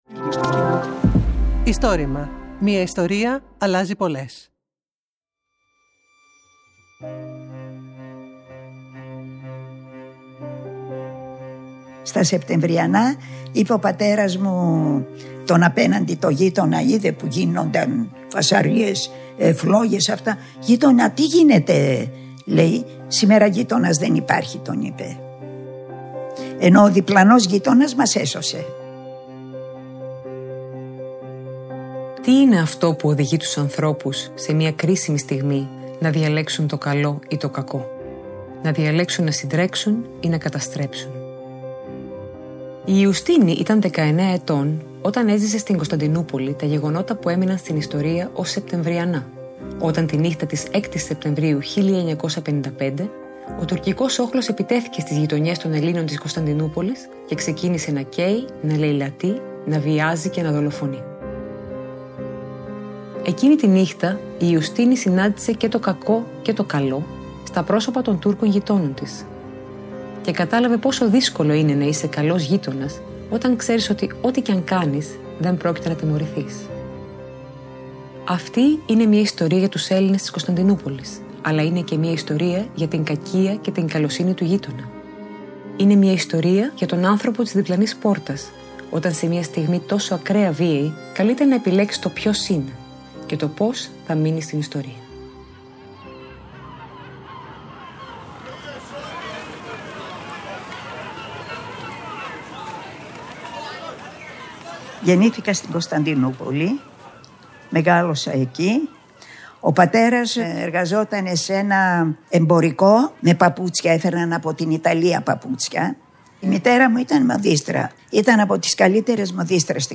Αφηγήτρια